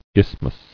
[isth·mus]